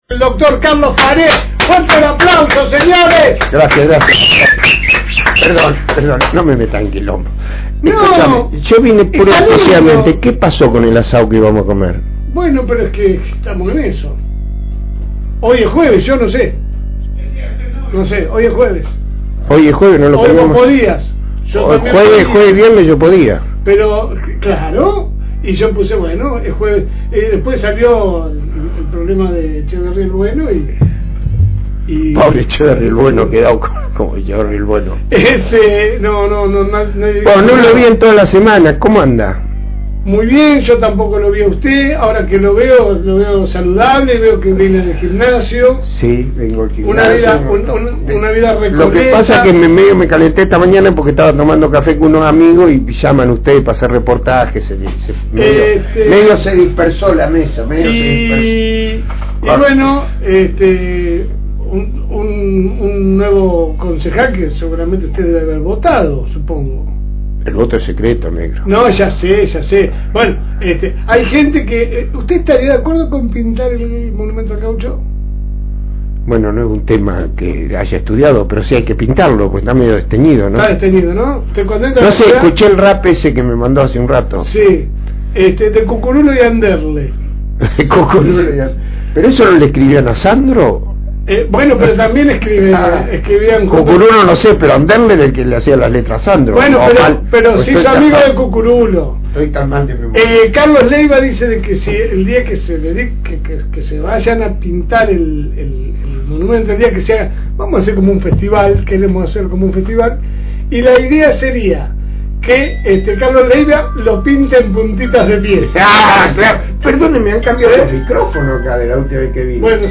Charla política